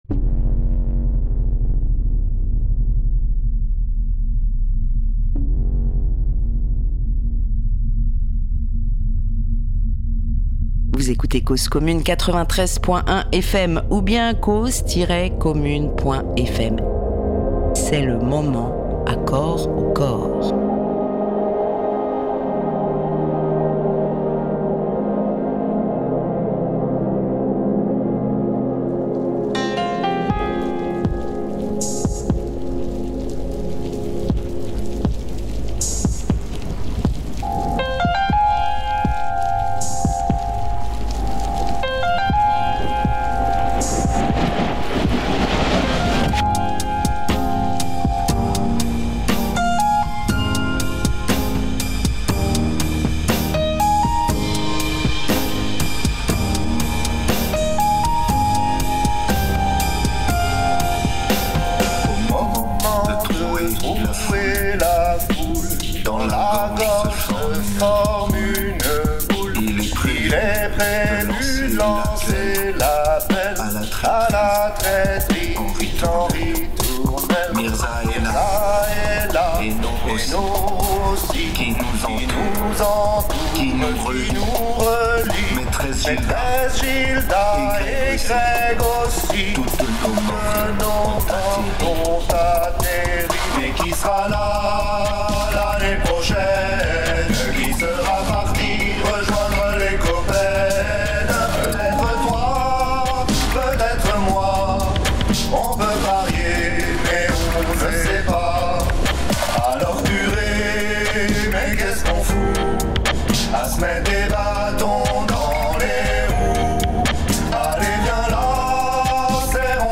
vidéo diffusée sur écran géant à l’occasion de cette commémoration et relatant l’historique des TDoR depuis leur origine, en 1999.